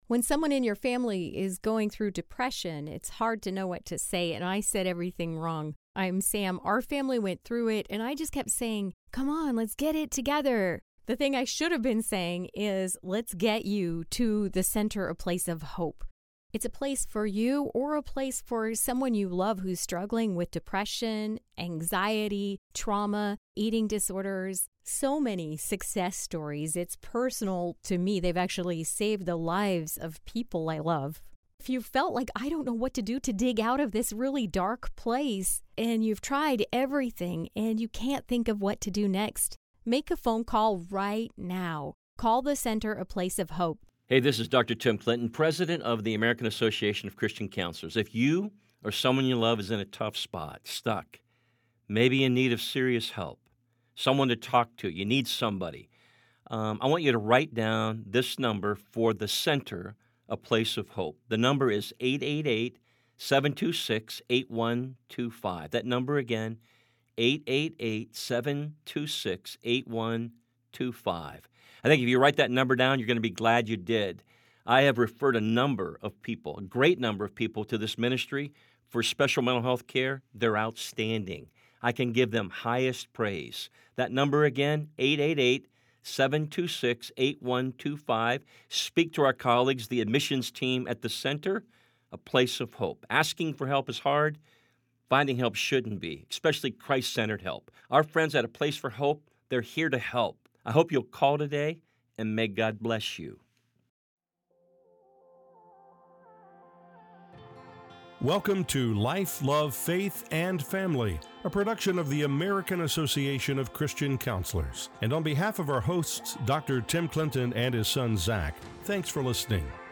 In this enlightening conversation